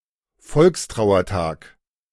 Volkstrauertag (German: [ˈfɔlkstʁaʊ̯ɐˌtaːk]
De-Volkstrauertag.ogg.mp3